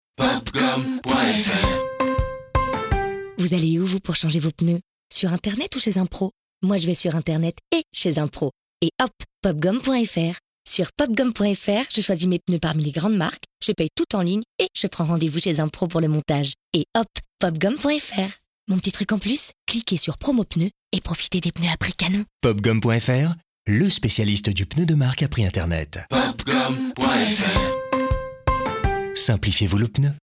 Vous avez s’en doute entendu une de leur pub à la radio, ♬♪ Pop Gom point fr ♬♪, on vous la remet :
popgom-pub-radio.mp3